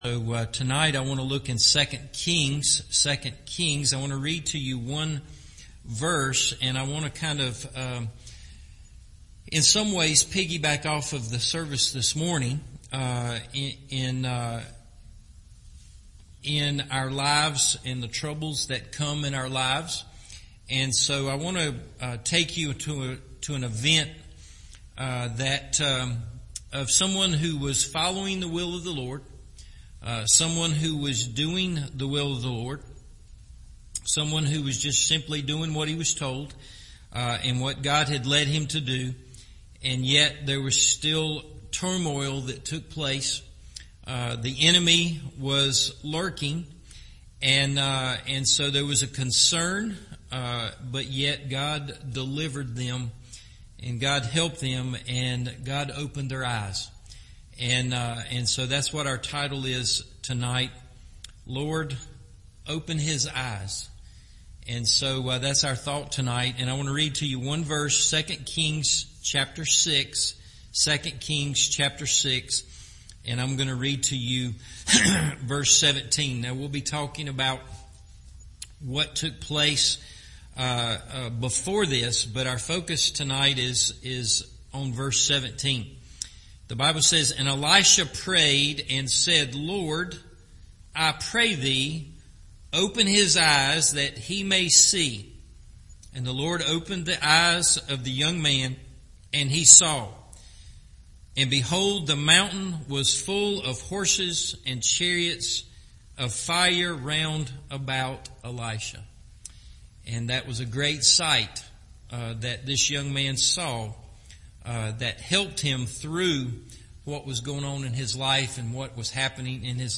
LORD, Open His Eyes – Evening Service